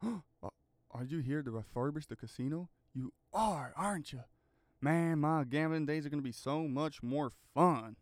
GASP A Are you here ti refurbish the casino, you are arent you.wav